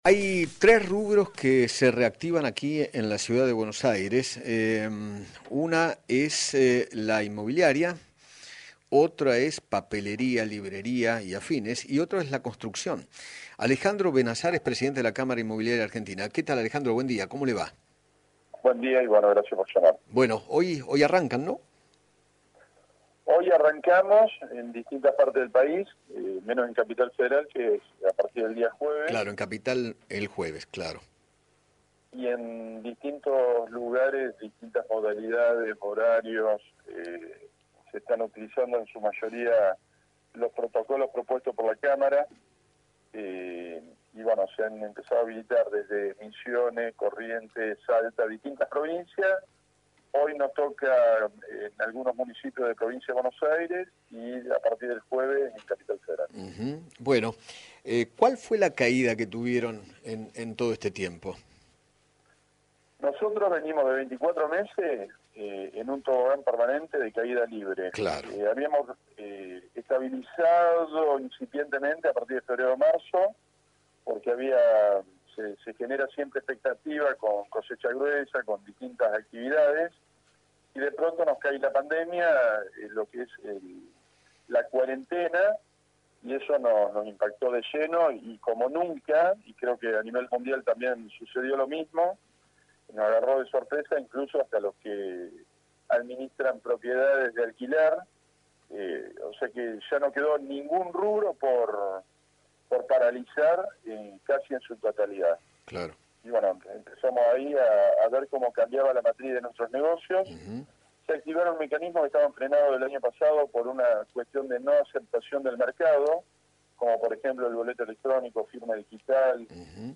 dialogó con Eduardo Feinmann sobre la reactivación del sector, a partir de esta semana, en todo el país.